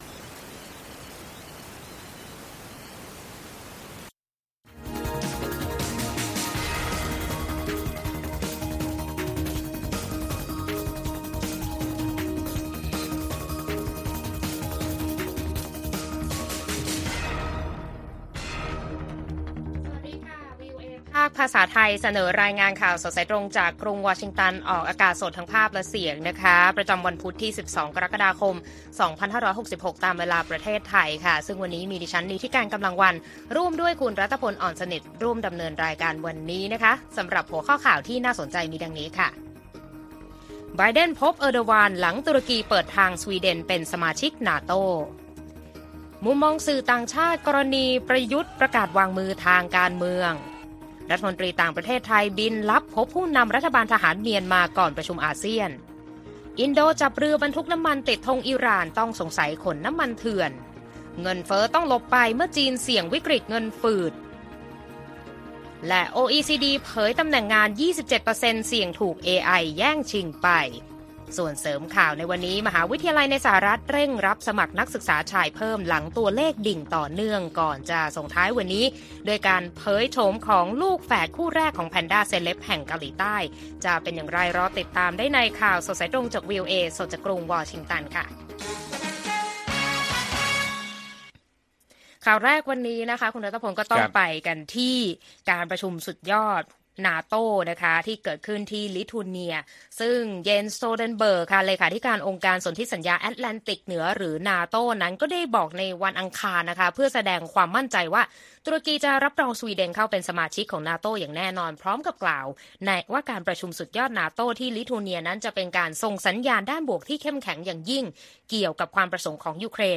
ข่าวสดสายตรงจากวีโอเอ ไทย พุธ 12 กรกฎาคม 2566